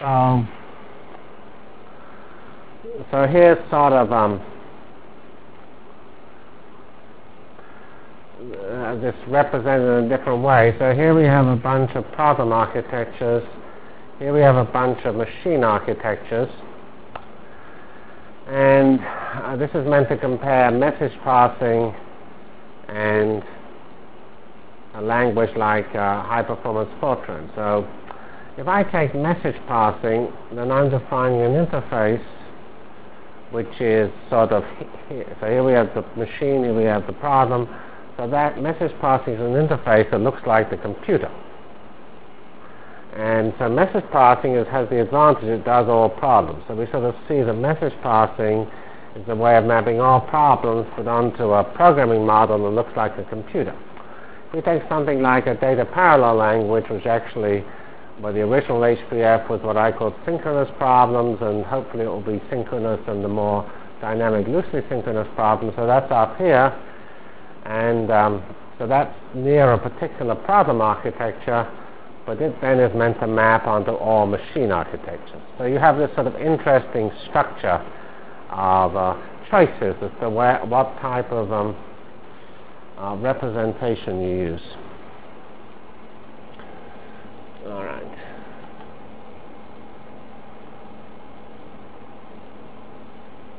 Delivered Lectures of CPS615 Basic Simulation Track for Computational Science